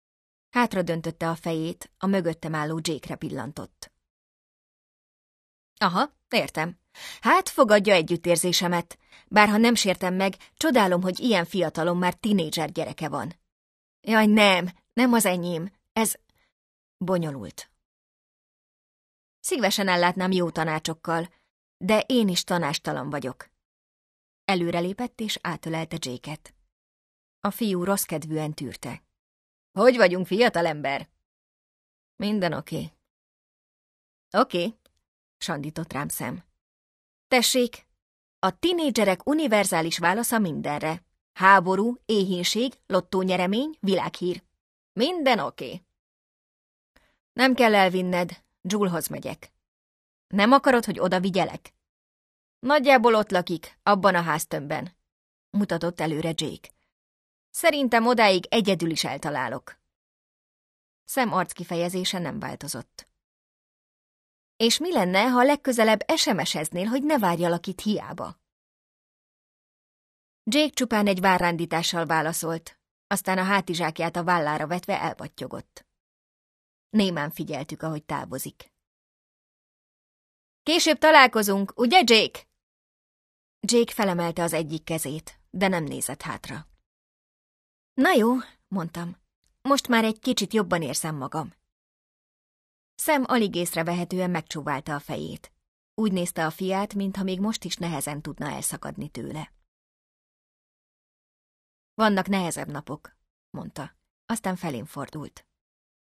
Online hangoskönyv